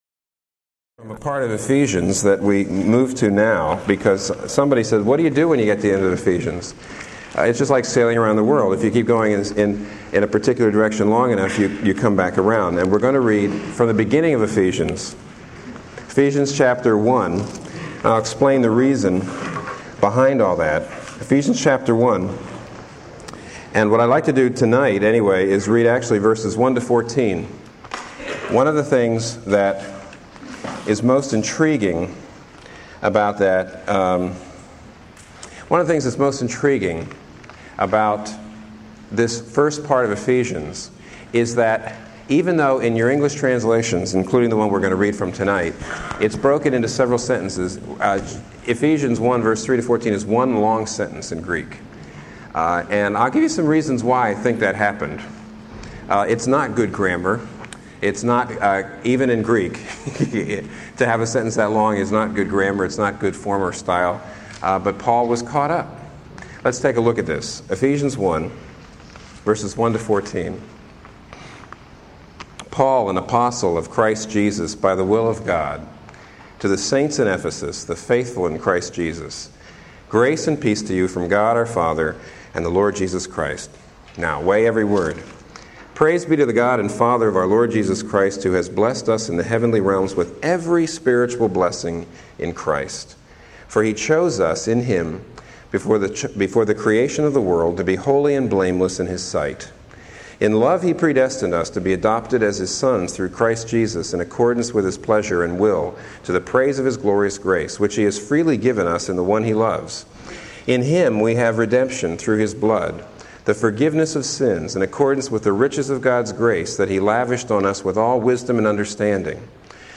Sermons | Local Church